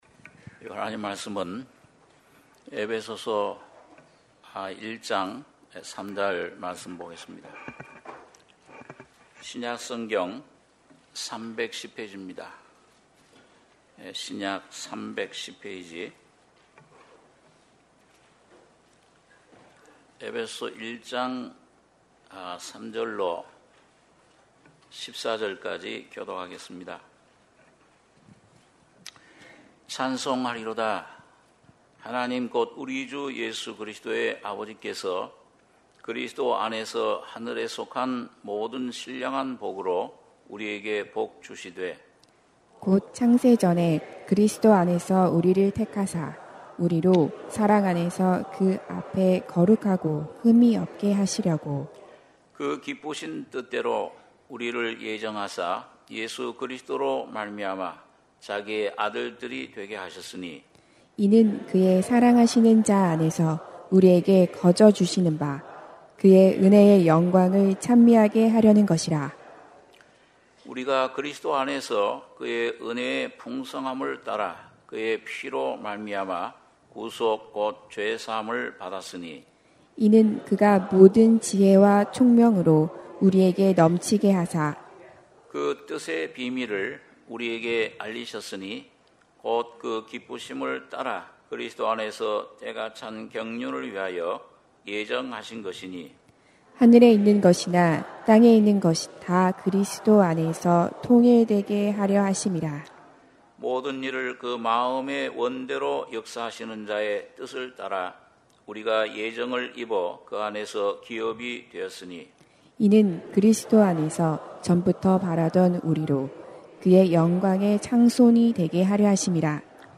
주일예배 - 에베소서 1장 3절~14절 주일1부